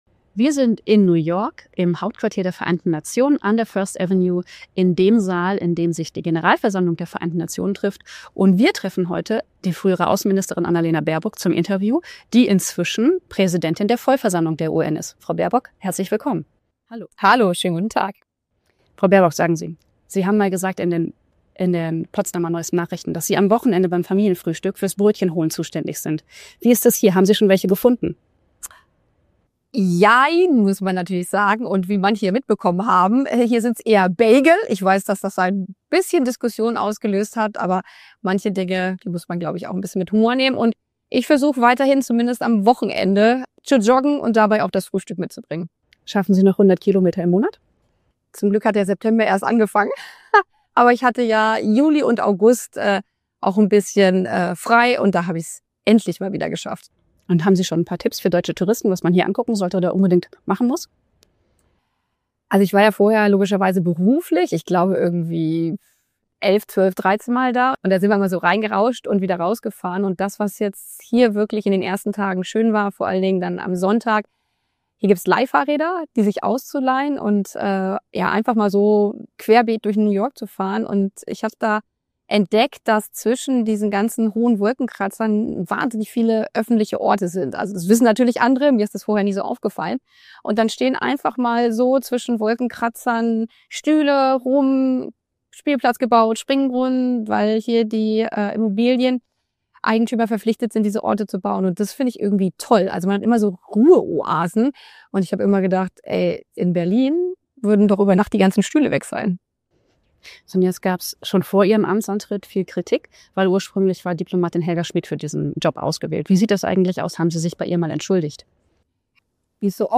Interview mit Annalena Baerbock über ihr neues Amt als UN-Präsidentin.